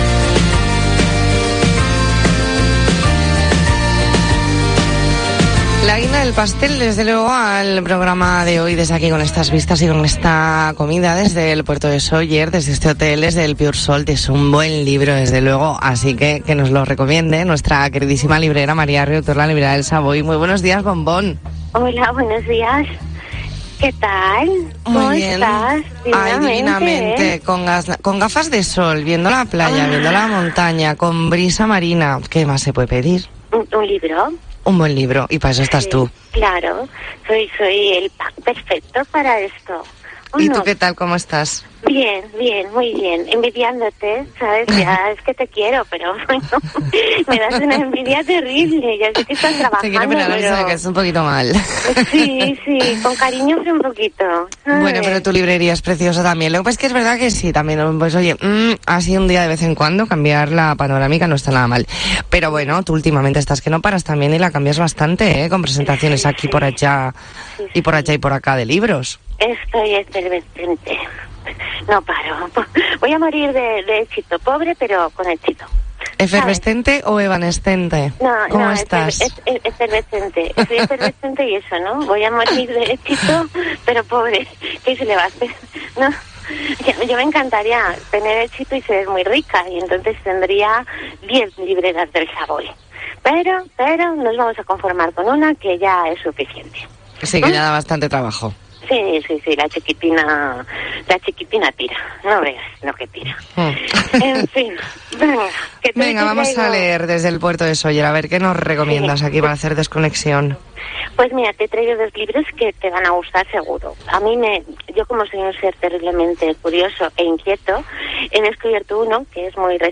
Entrevista en 'La Mañana en COPE Más Mallorca', martes 24 de mayo de 2022.